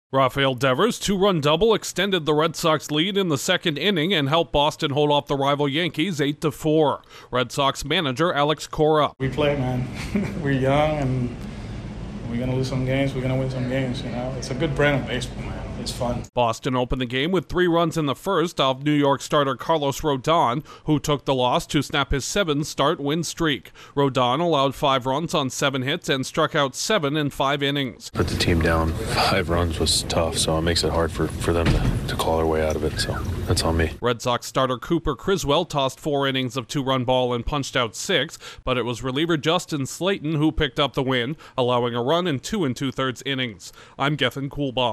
The Red Sox force a rubber match in their three-game series against the rival Yankees. Correspondent